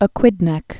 (ə-kwĭdnĕk)